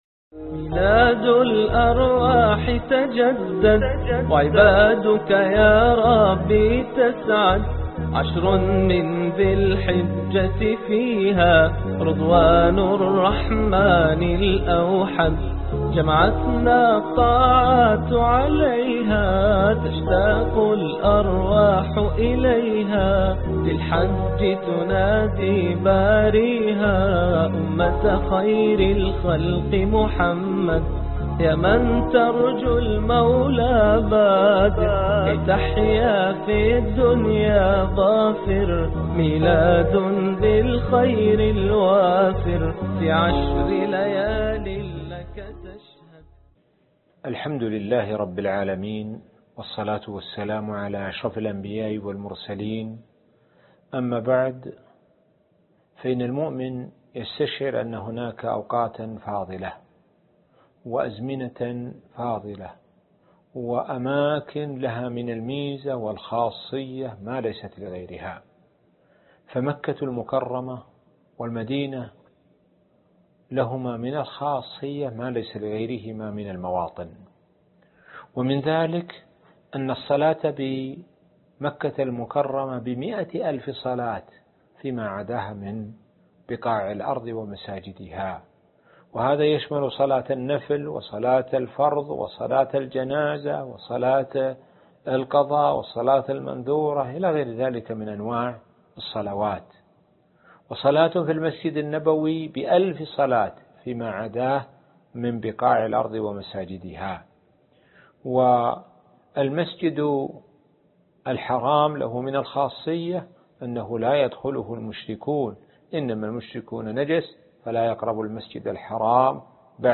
الدرس الخامس والعشرون ( ميلاد جديد - الحج ) - الشيخ سعد بن ناصر الشثري